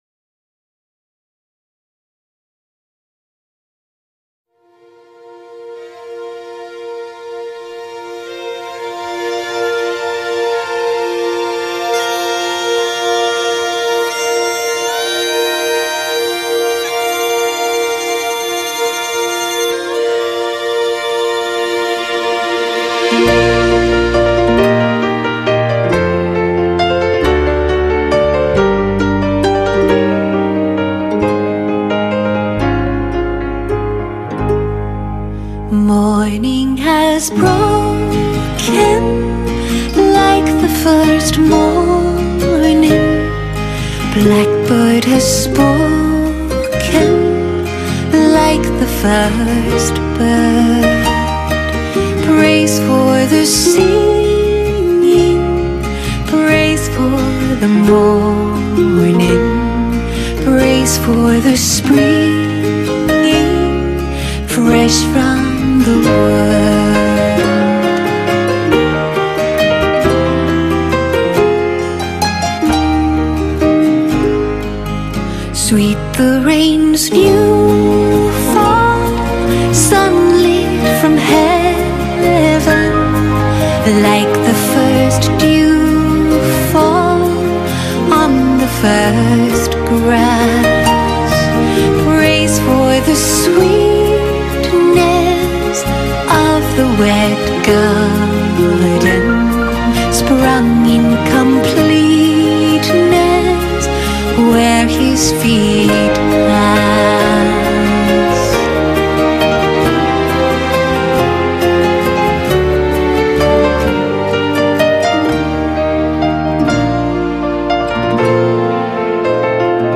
Gaelic tune